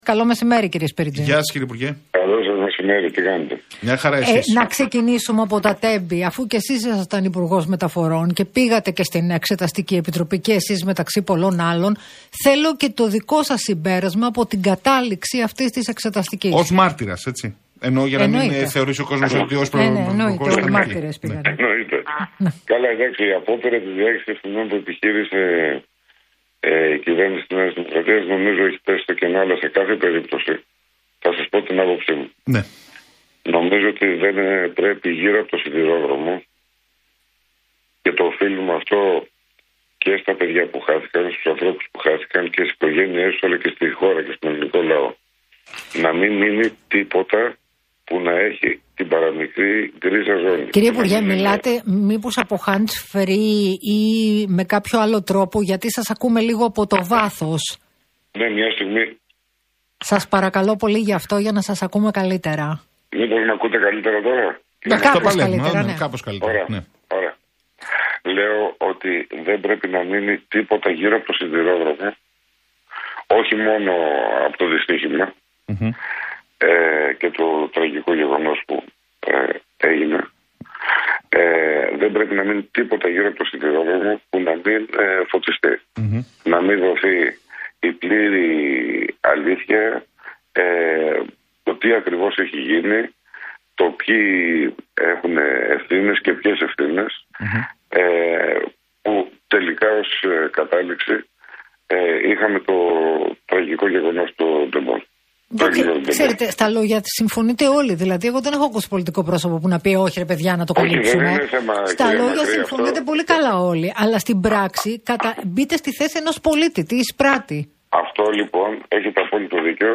Ολόκληρη η συνέντευξη του κ. Σπίρτζη για τα εσωκομματικά του ΣΥΡΙΖΑ έχει ως εξής: